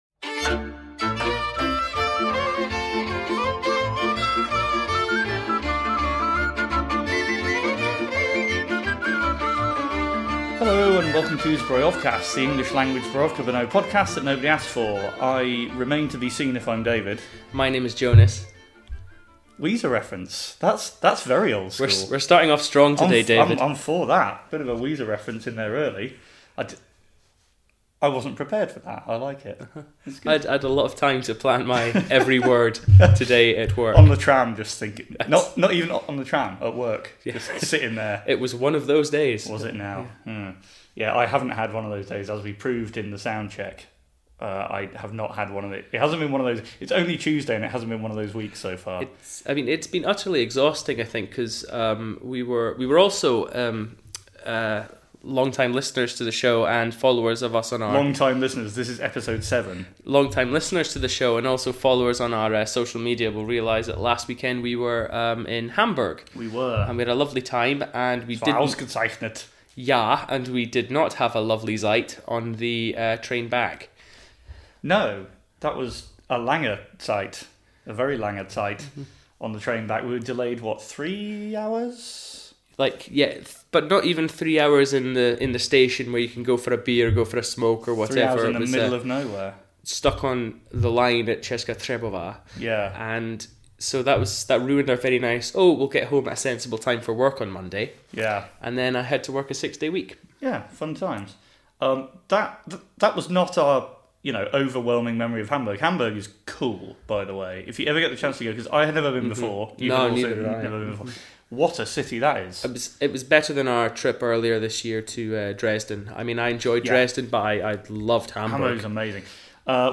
A James Richardson impersonation, "the Pickle Boys", double beer-of-the-podcast, a lucky Austrian, Tynecastle memories, and some non-Australian singing.